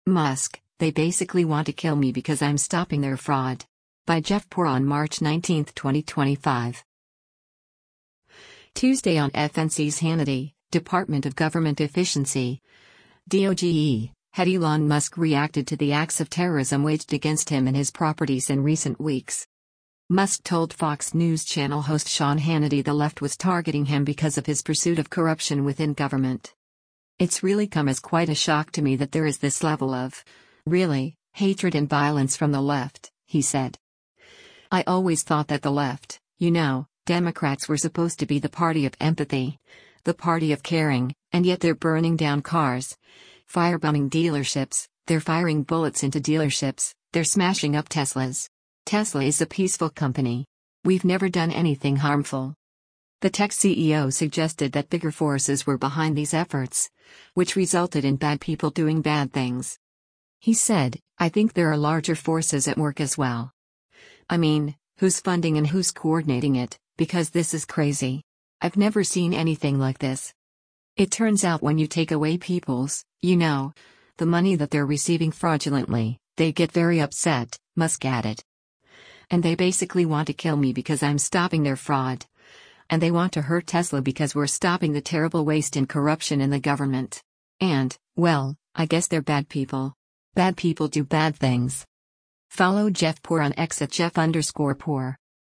Tuesday on FNC’s “Hannity,” Department of Government Efficiency (DOGE) head Elon Musk reacted to the acts of terrorism waged against him and his properties in recent weeks.
Musk told Fox News Channel host Sean Hannity the left was targeting him because of his pursuit of corruption within government.